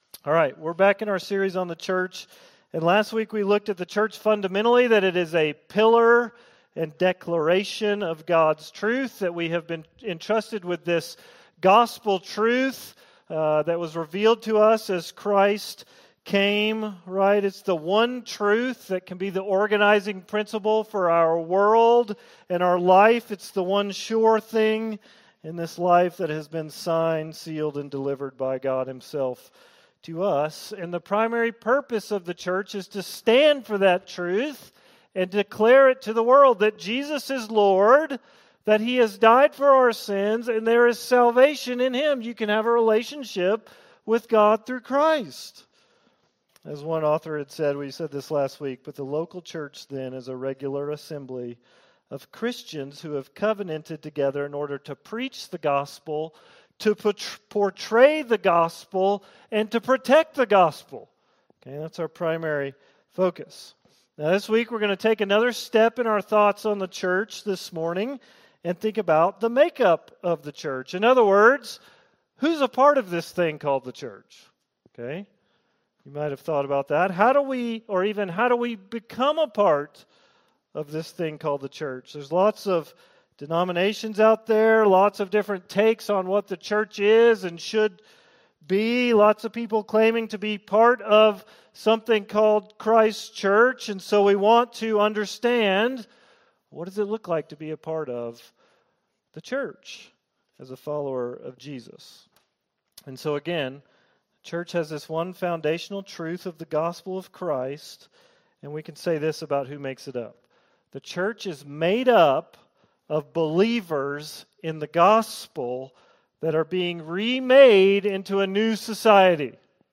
All Sermons - Risen Life Church